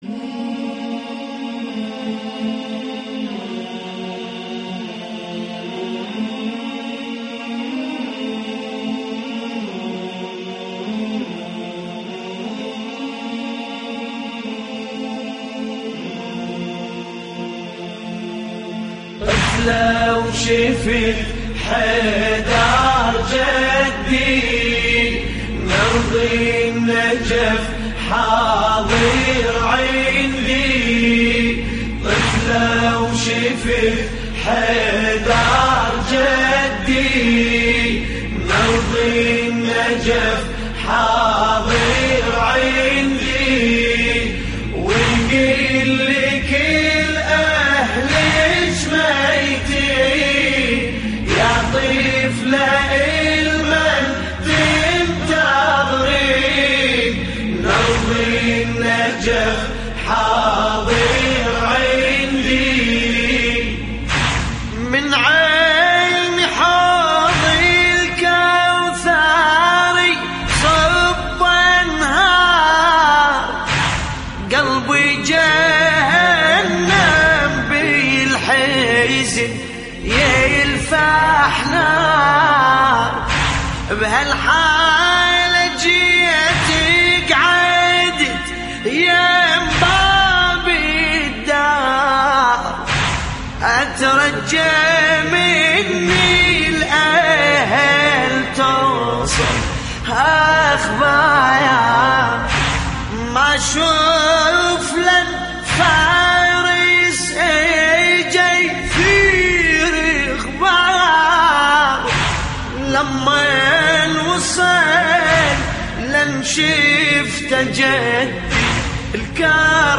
تحميل : طفلة وشفت حيدر جدي من ارض النجف حاضر عندي / الرادود باسم الكربلائي / اللطميات الحسينية / موقع يا حسين